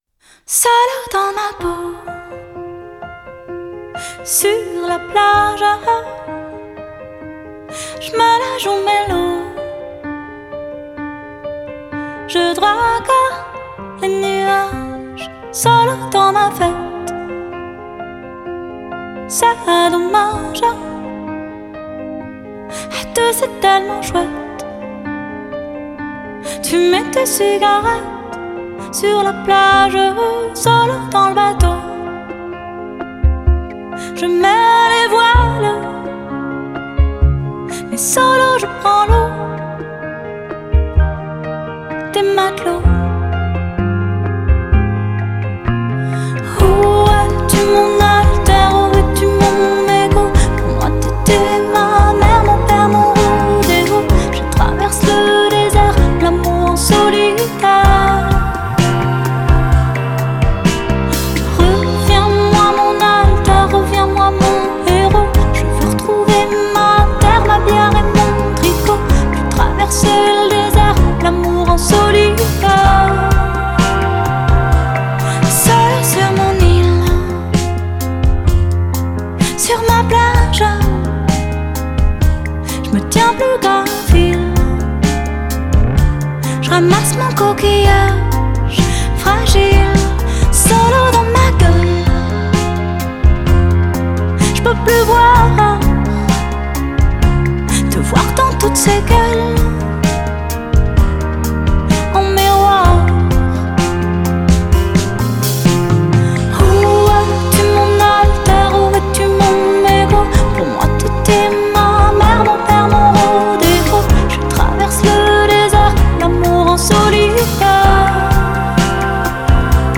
trippy